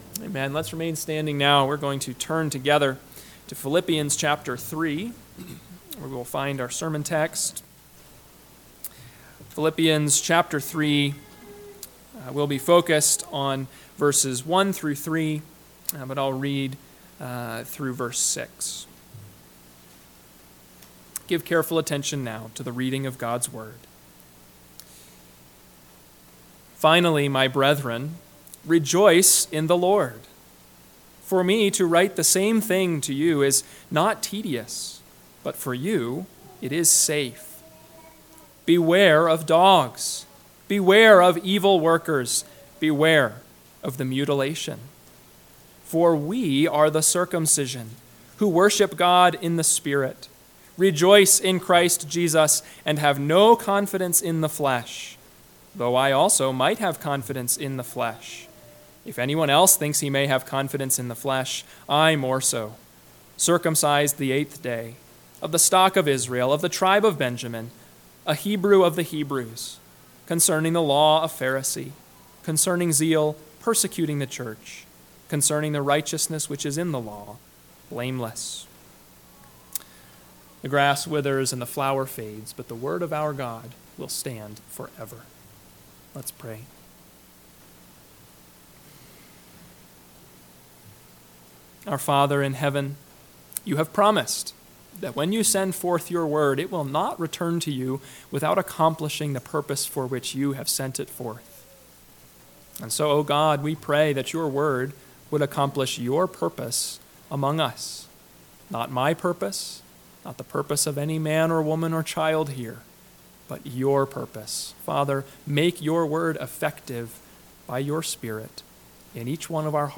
PM Sermon – 2/4/2024 – Philippians 3:1-3 – Watch Out!